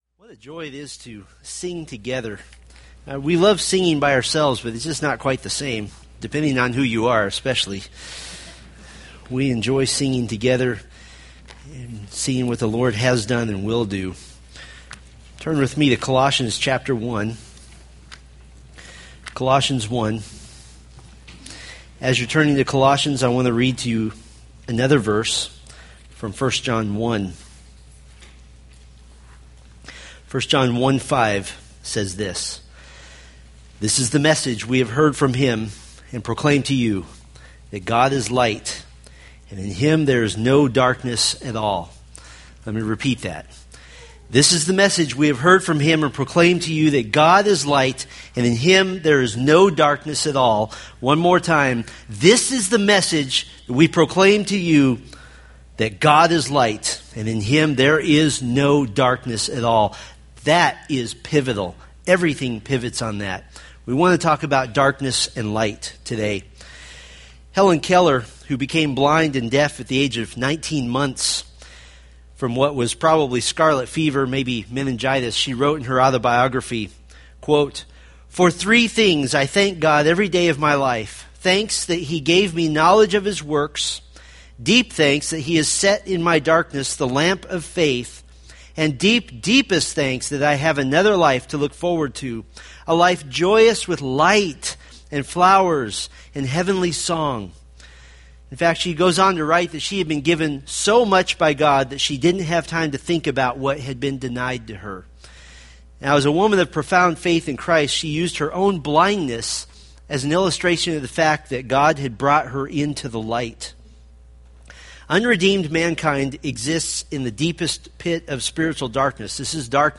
Colossians Sermon Series